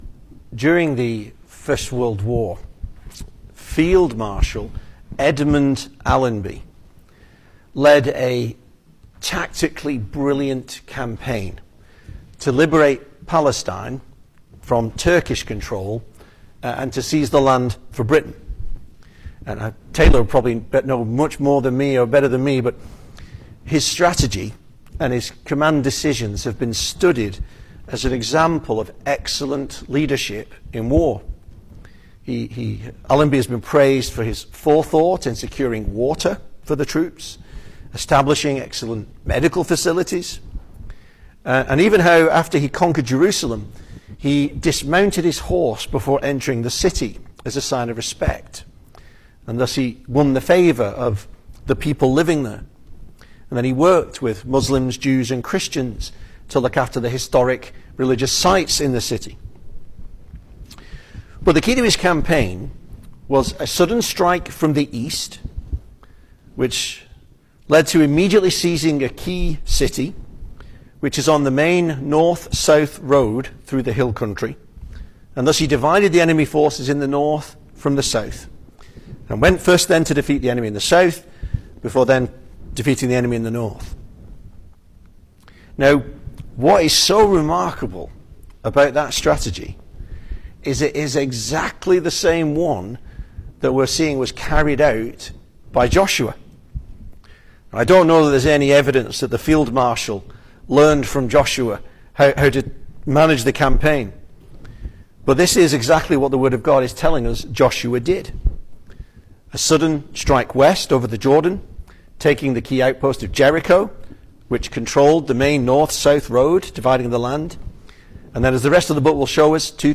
2026 Service Type: Sunday Evening Speaker